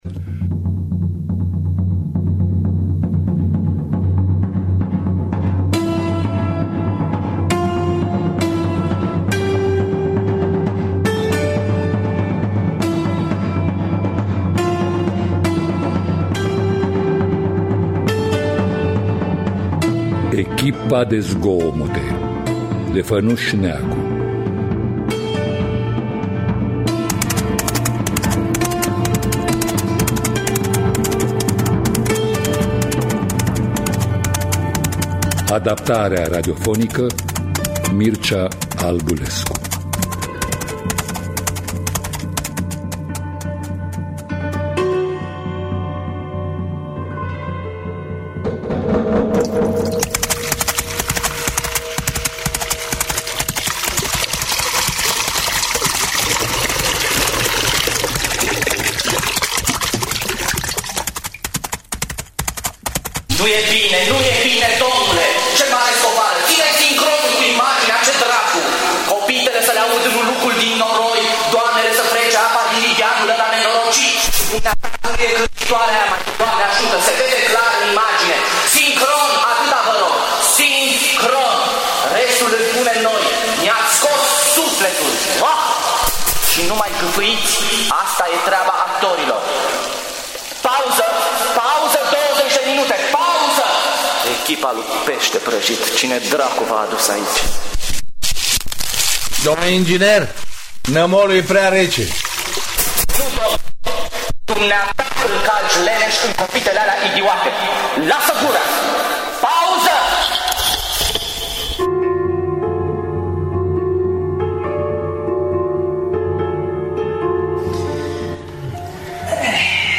Echipa de zgomote de Fănuş Neagu – Teatru Radiofonic Online